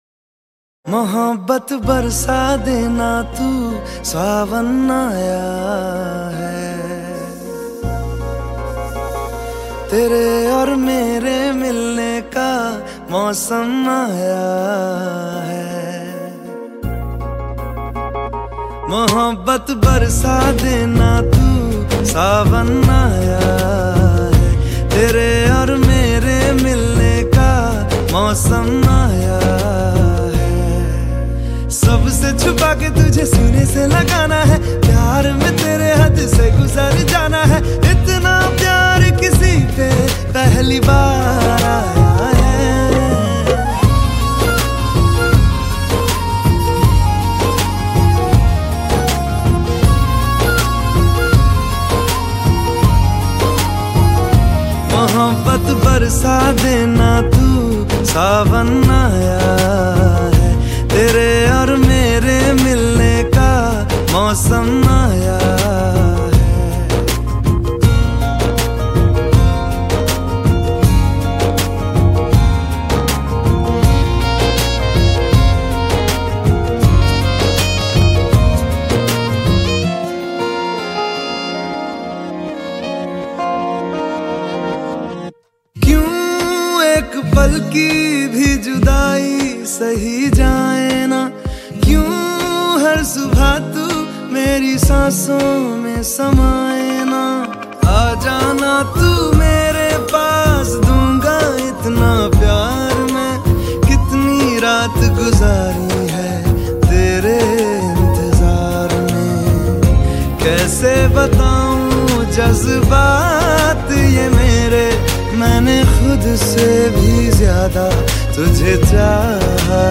Categories Baarish Ringtones / Rain Ringtones